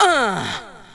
VOX SHORTS-1 0018.wav